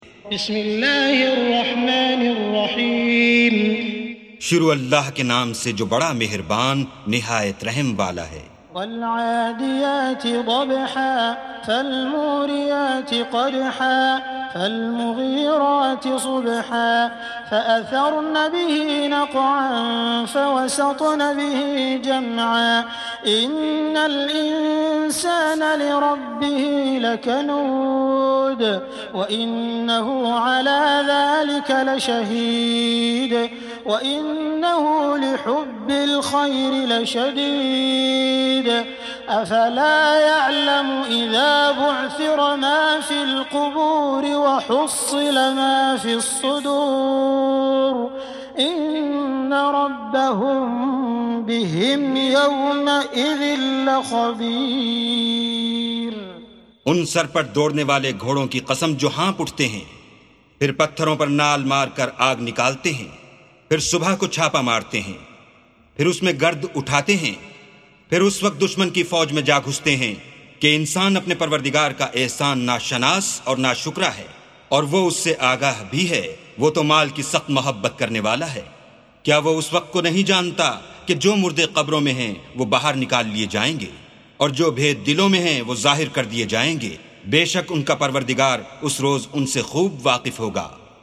سُورَةُ العَادِيَاتِ بصوت الشيخ السديس والشريم مترجم إلى الاردو